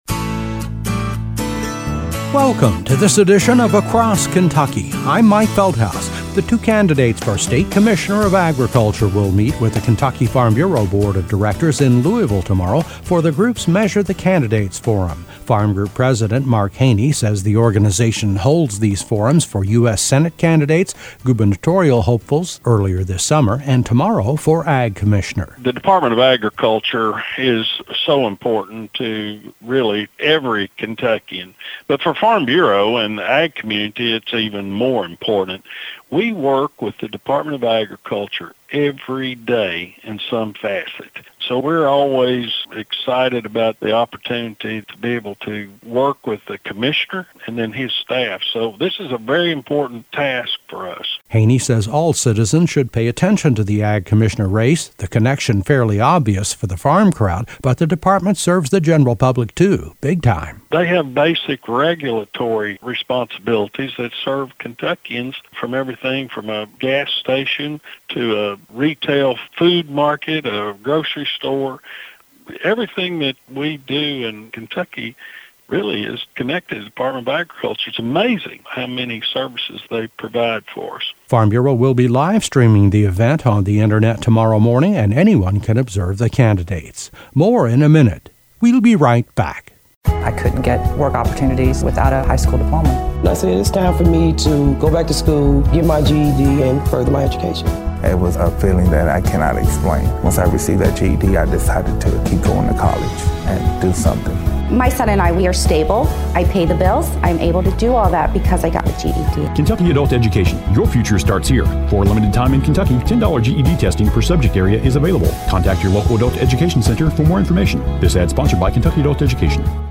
Across KentuckyA preview report as both candidates for commissioner of agriculture will meet with the Farm Bureau board of directors at the group’s Measure the Candidates forum in Louisville this Tuesday.